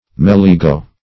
melligo - definition of melligo - synonyms, pronunciation, spelling from Free Dictionary Search Result for " melligo" : The Collaborative International Dictionary of English v.0.48: Melligo \Mel*li"go\, n. [L.]